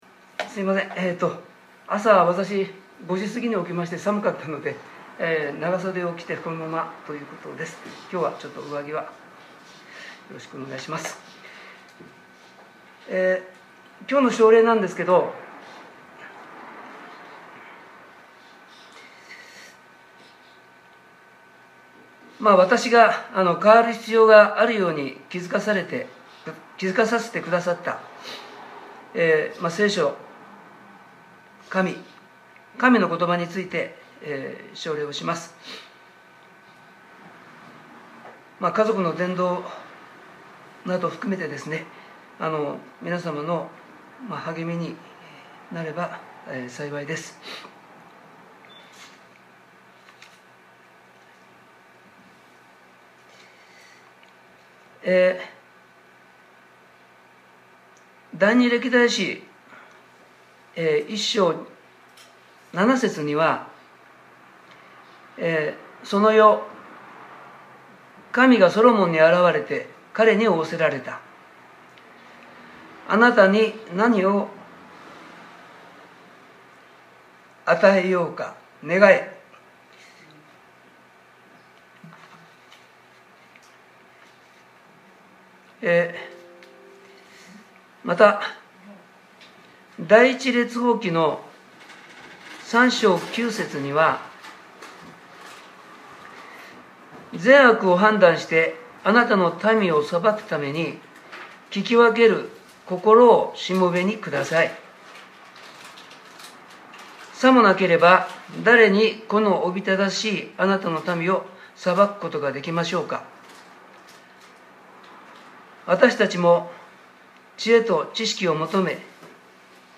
2021年05月30日（日）礼拝説教『 メンタリング・奨励 』